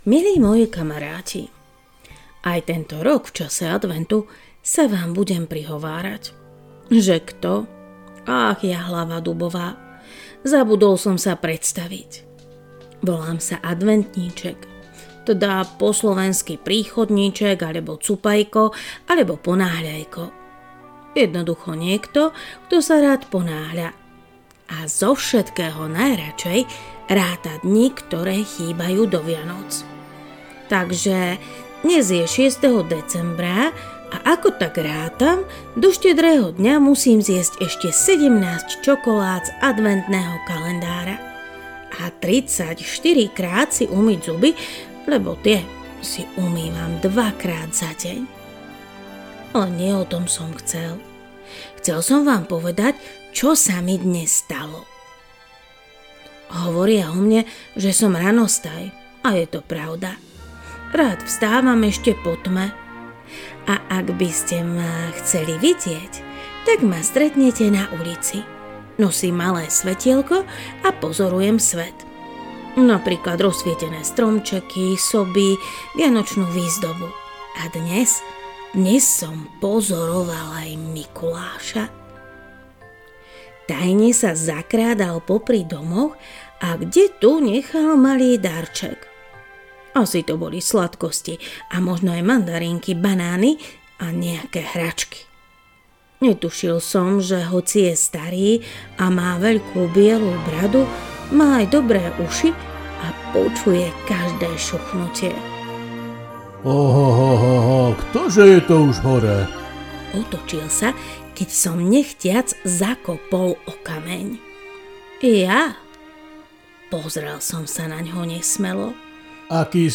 Adventné príbehy pre deti